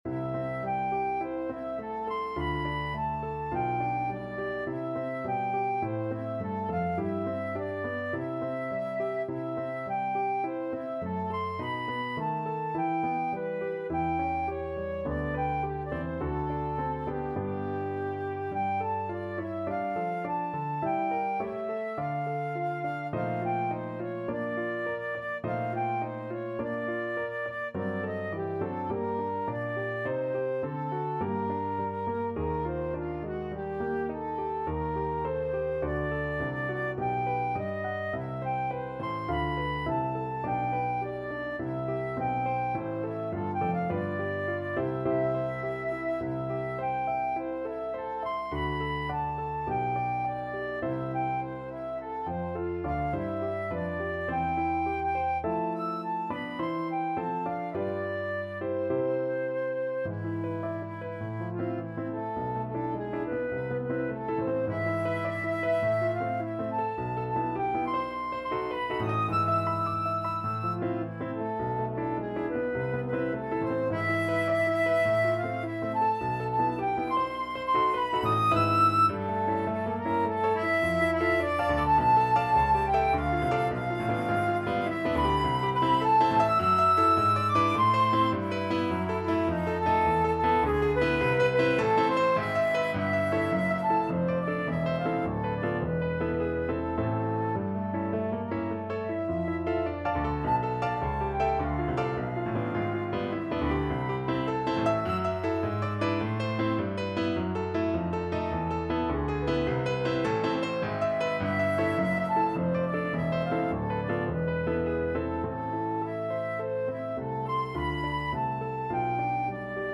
Flute
4/4 (View more 4/4 Music)
C major (Sounding Pitch) (View more C major Music for Flute )
Einfach, innig =104
Classical (View more Classical Flute Music)